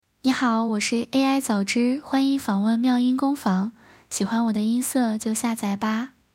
早芝 少萝音 能唱歌的RVC模型
本次训练了一款新的RVC模型，取名为早芝，该模型使用了2个音色差不多的少萝数据集，在训练方面使用的是V1006.2版本训练而成，对低音部分对处理会更加好。